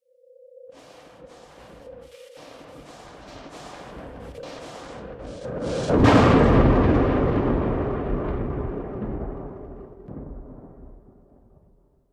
emi_blowout_02.ogg